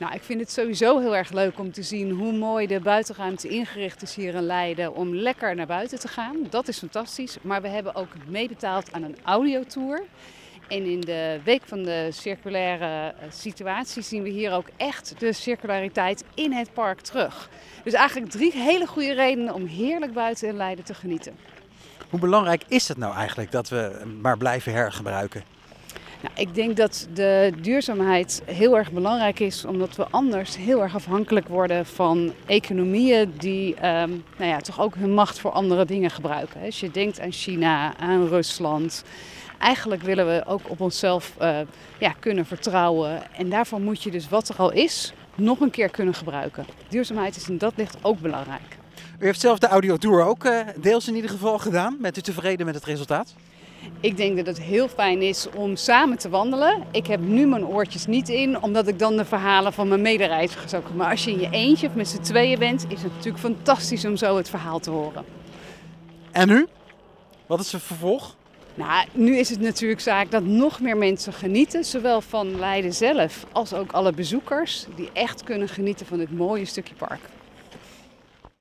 spreekt met gedeputeerde Anne Koning over de blik van de provincie op circulair werken in Leiden